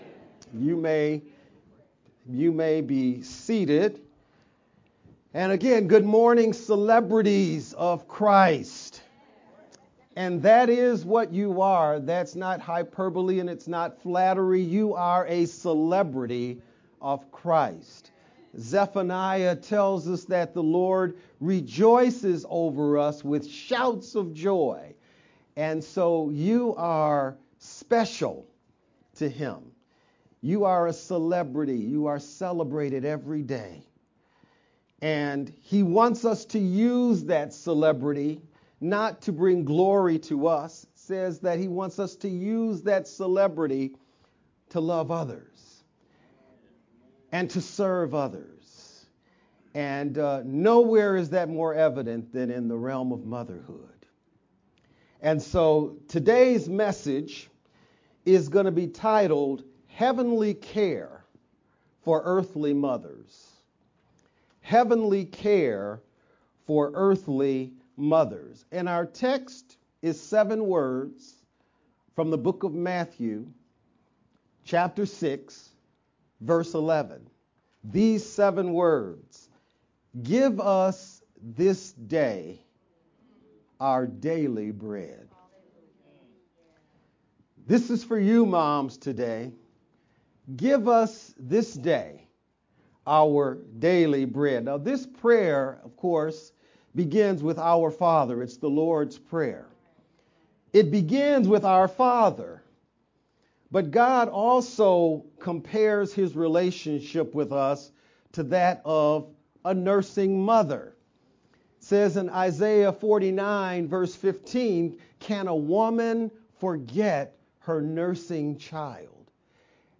VBCC-Sermon-sermon-only-May-12th_Converted-CD.mp3